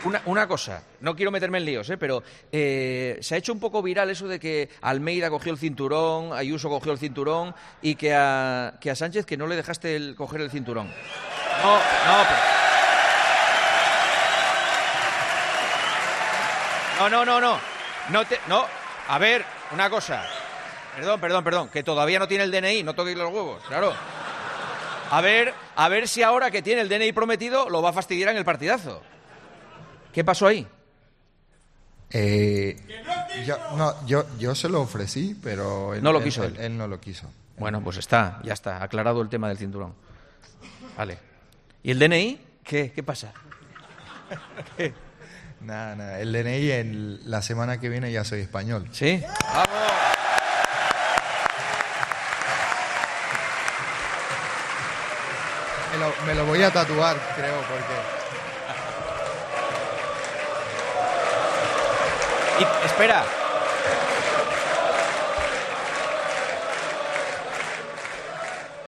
En ese momento, el público de El Partidazo de COPE en Alicante se volvió loco.
Ilia Topuria con su cinturón de campeón de la UFC en el programa especial de El Partidazo de COPE